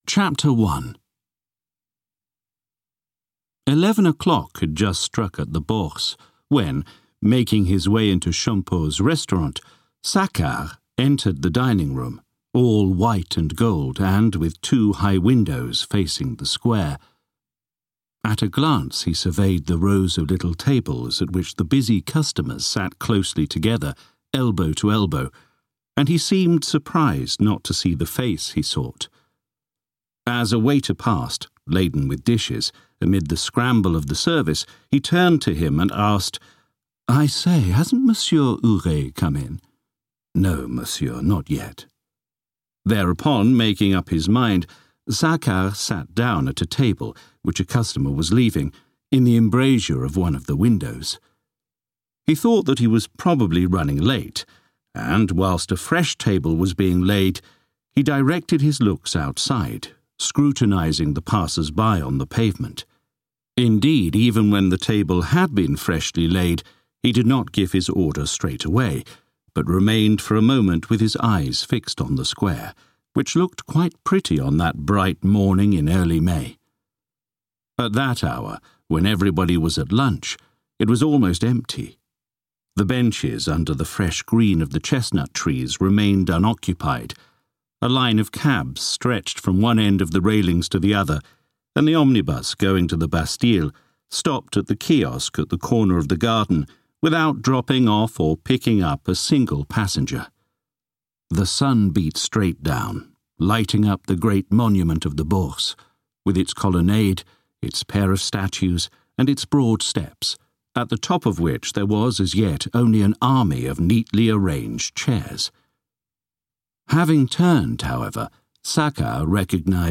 Money audiokniha
Ukázka z knihy